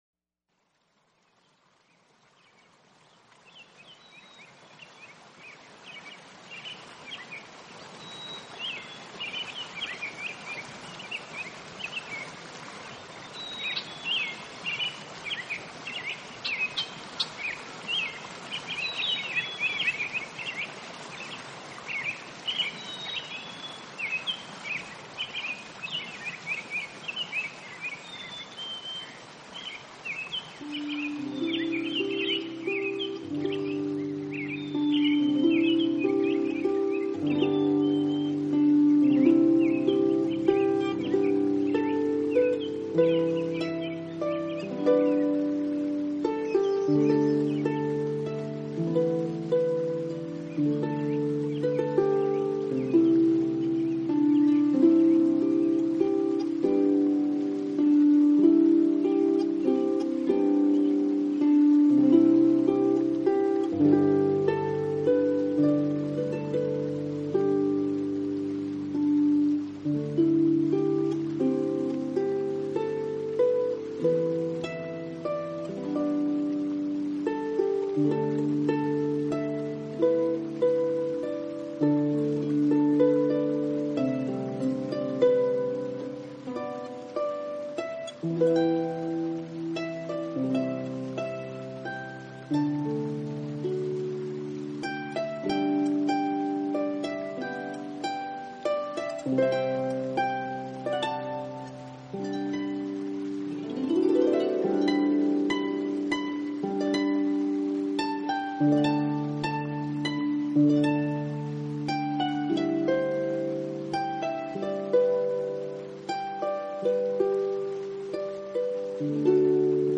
竖琴这一古老的乐器始终有一种让人无法抗拒的典雅之美，其音色平和、舒缓
竖琴与自然音效做了有机的结合，
竖琴成为音乐的主角。